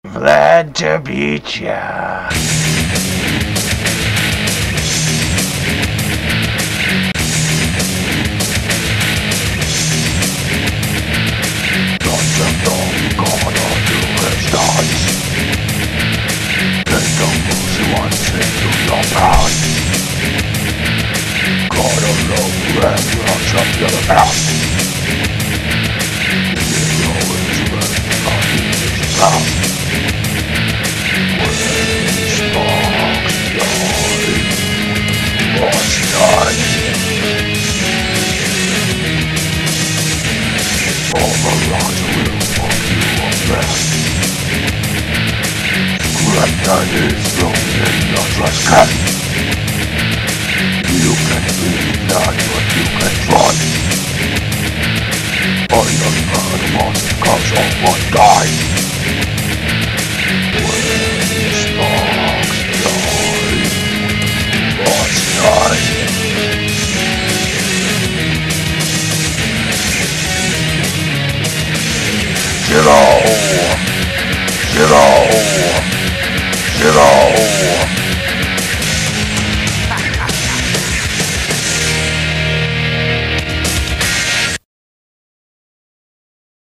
When Sparks Fly (Bots Die)- This song is a tribute to one of the greatest shows in existance: Battlebots! This song is just simply random rhyming phrases about battlebots strung together and "sang" over an extended version of the battlebots theme song.